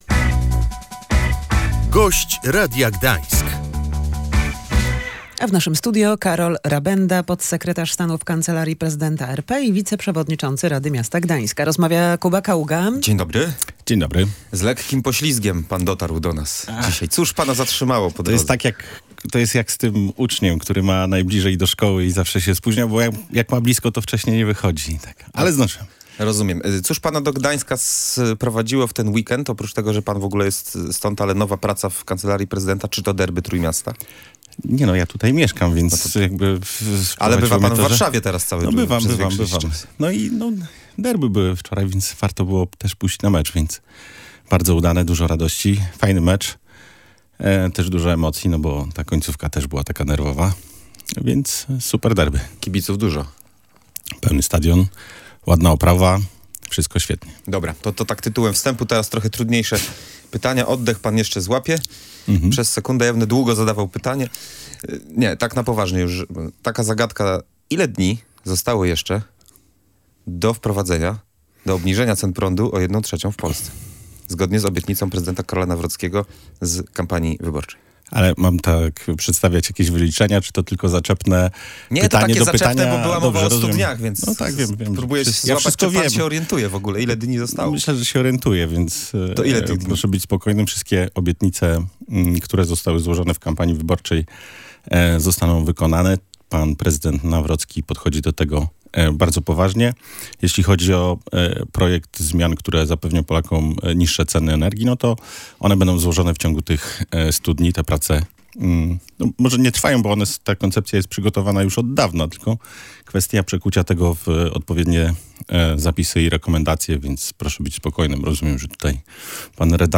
Karol Nawrocki, tak jak obiecał, w ciągu stu dni przedstawi projekt zapewniający niższe ceny energii – mówił w Radiu Gdańsk Karol Rabenda, podsekretarz stanu w Kancelarii Prezydenta RP i wiceprzewodniczący Rady Miasta Gdańska.
Gość Radia Gdańsk